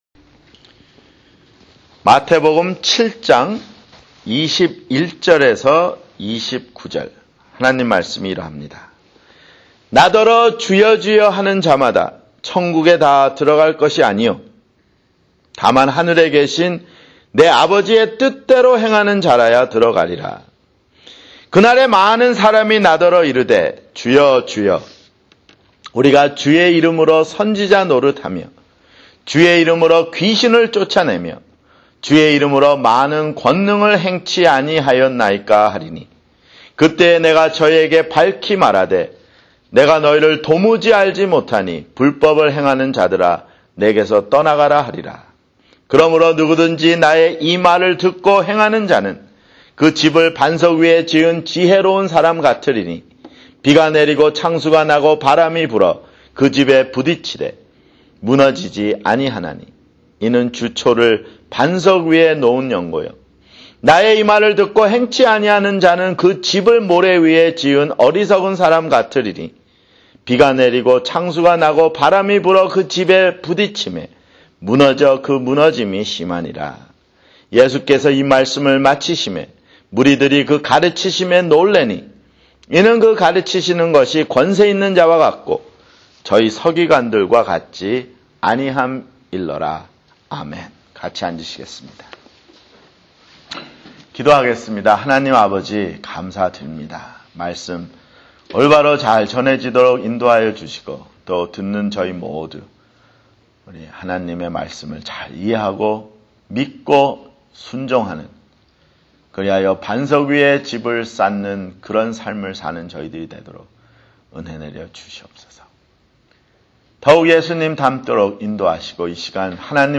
[주일설교] 마태복음 (47)